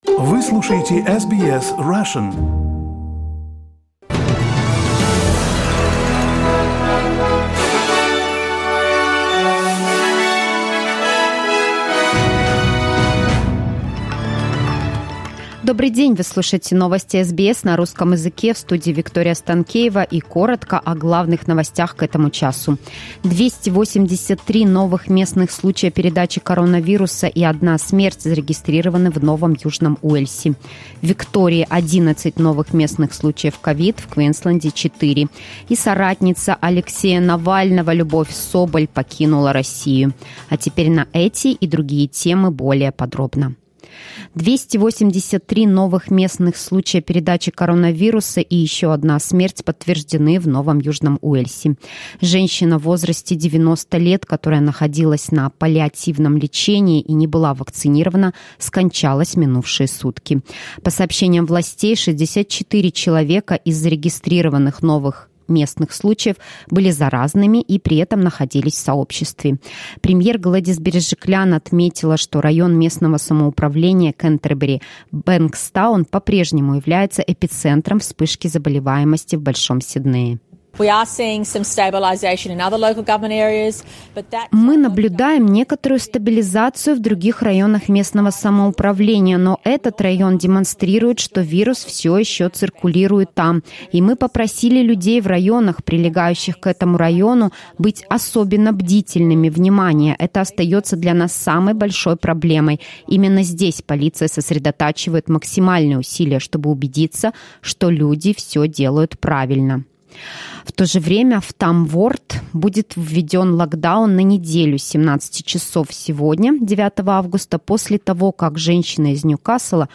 Новости SBS на русском языке - 9.08